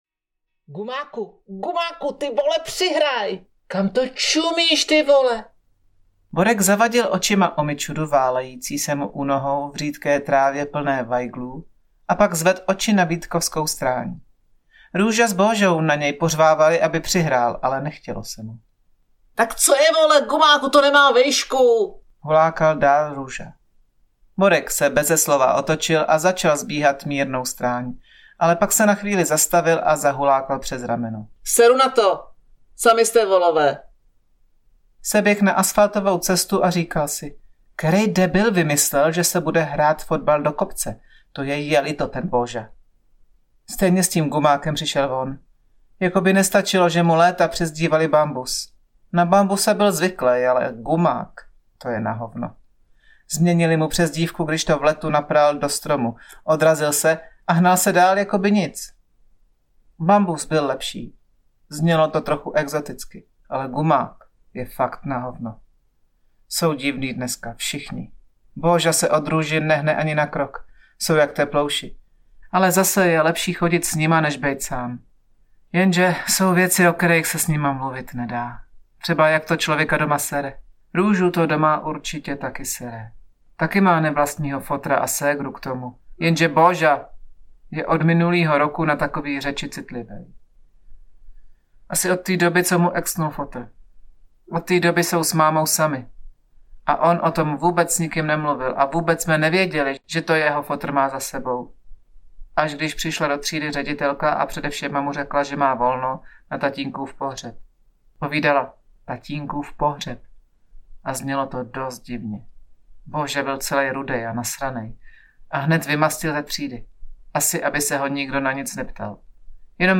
Všichni sou trapný audiokniha
Ukázka z knihy